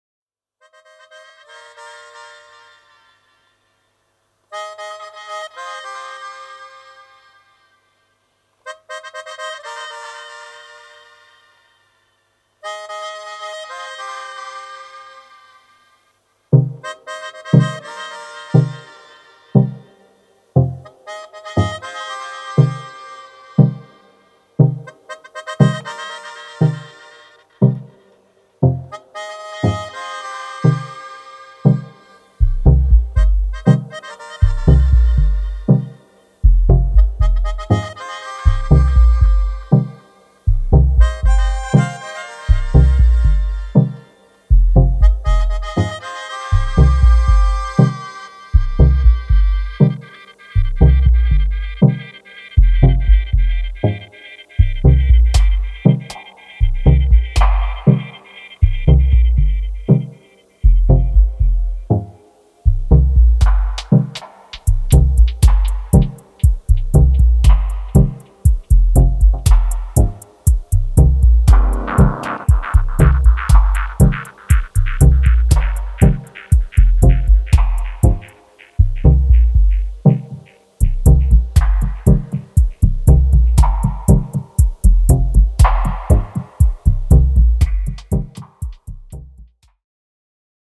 Techno Dub